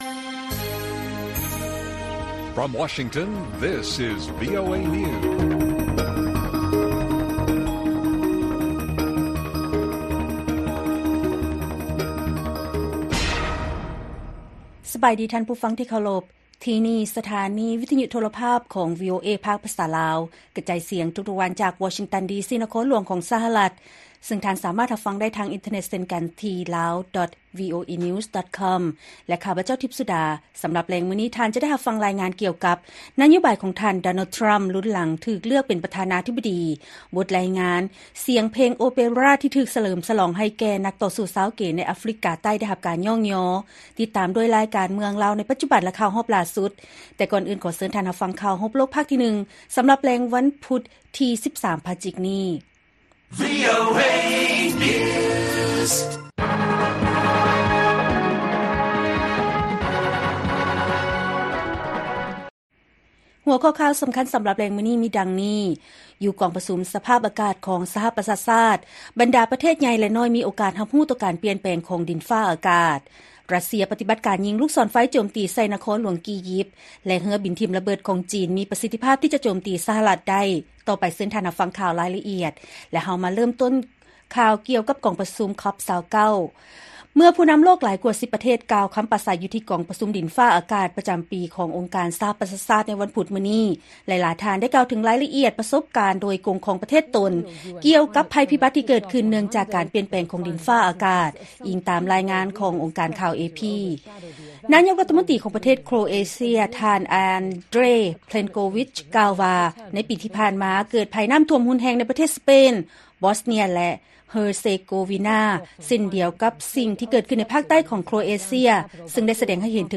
ວີໂອເອພາກພາສາລາວ ກະຈາຍສຽງທຸກໆວັນ, ຫົວຂໍ້ຂ່າວສໍາຄັນສໍາລັບແລງມື້ນີ້ ມີດັ່ງນີ້: ຢູ່ກອງປະຊຸມສະພາບອາກາດຂອງສະຫະປະຊາຊາດ, ບັນດາປະເທດໃຫຍ່ ແລະນ້ອຍ ມີໂອກາດຮັບຮູ້ຕໍ່ການປ່ຽນແປງຂອງດິນຟ້າອາກາດ, ຣັດເຊຍ ປະຕິບັດການຍິງລູກສອນໄຟໂຈມຕີໃສ່ນະຄອນຫຼວງ ກີຢິບ, ແລະ ເຮືອບິນຖິ້ມລະເບີດຂອງ ຈີນ ມີປະສິດທິພາບທີ່ຈະໂຈມຕີ ສຫລ ໄດ້.